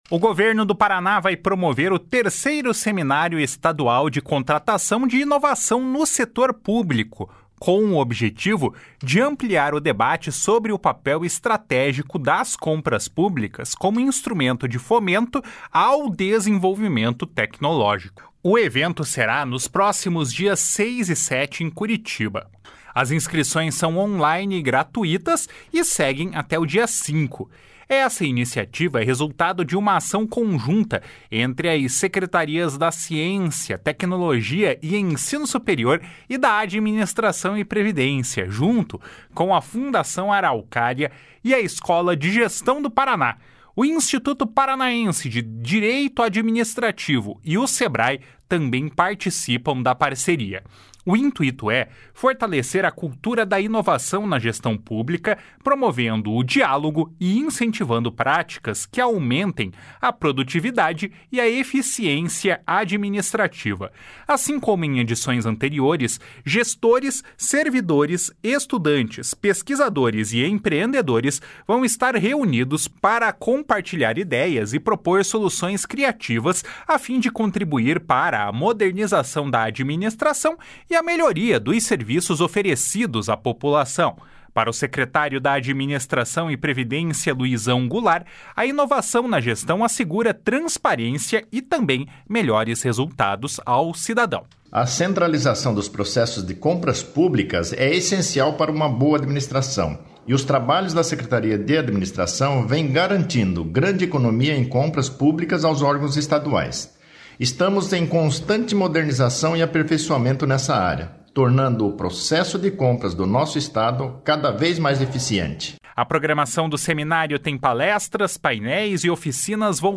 // SONORA LUIZÃO GOULART //